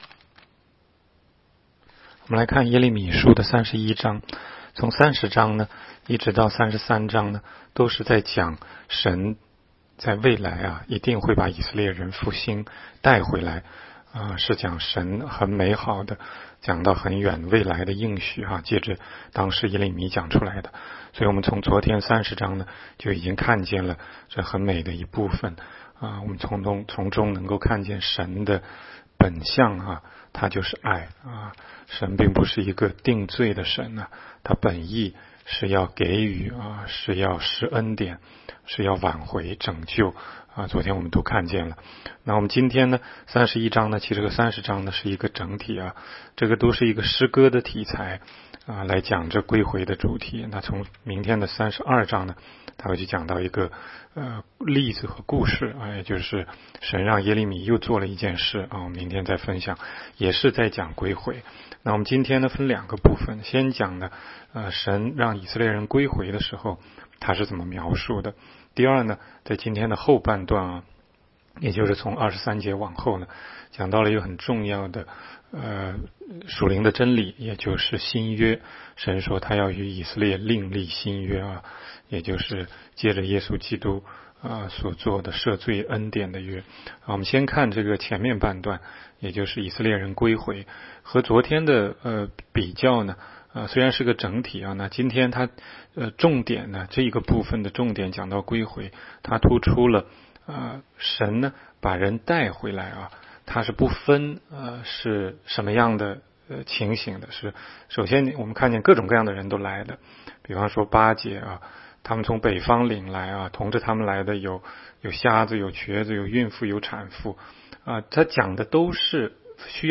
16街讲道录音 - 每日读经 -《耶利米书》31章